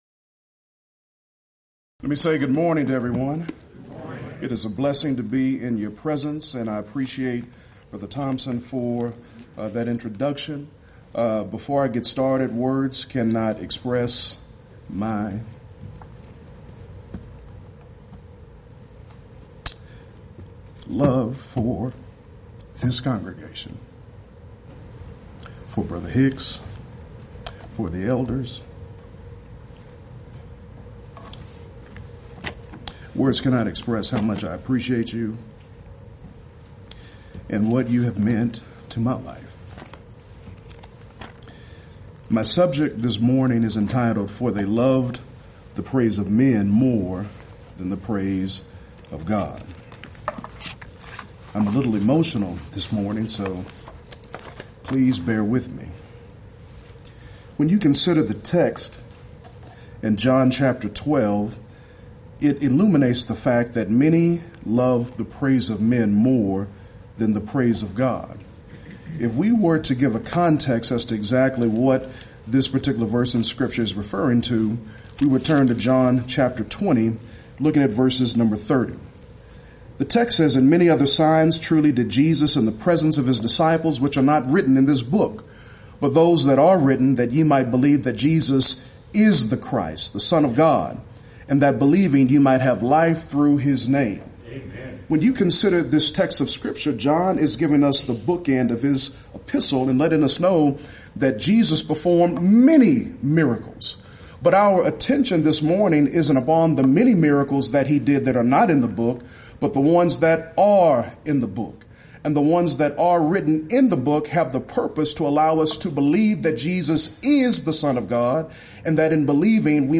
Event: 26th Annual Lubbock Lectures Theme/Title: God is Love